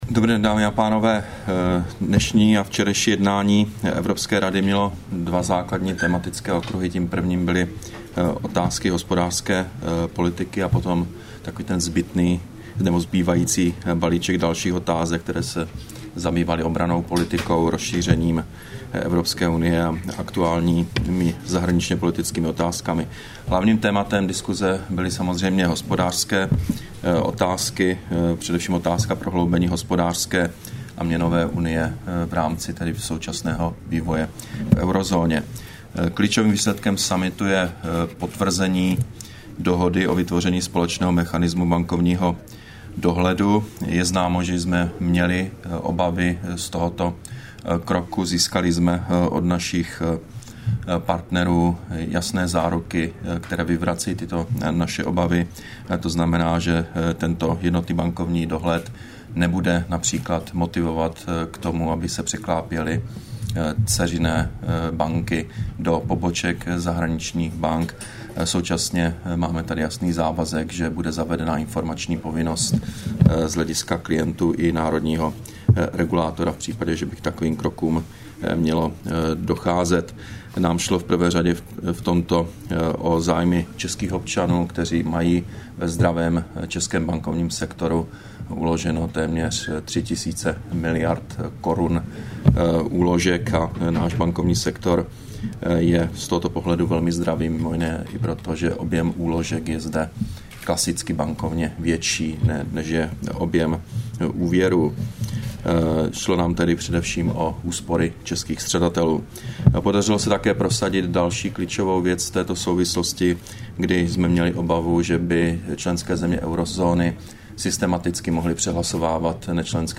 Brífink premiéra Petra Nečase po Evropské radě, 14. prosince 2012